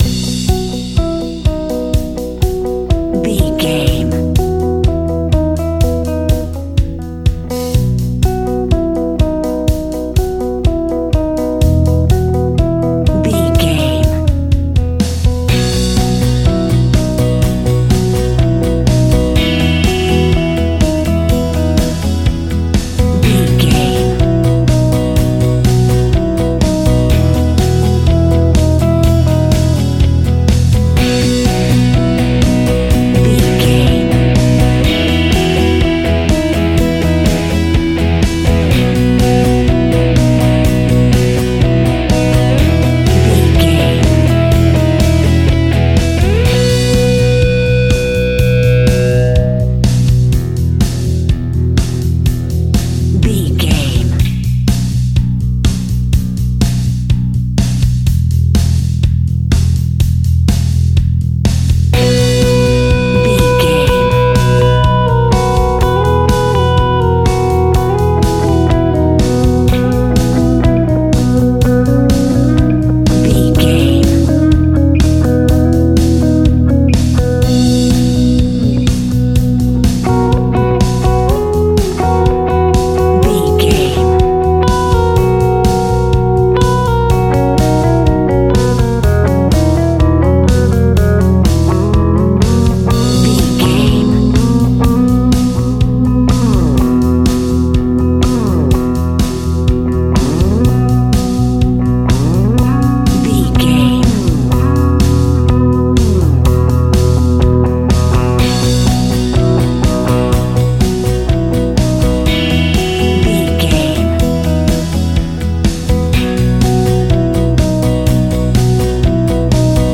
Ionian/Major
fun
energetic
uplifting
instrumentals
guitars
bass
drums
piano
organ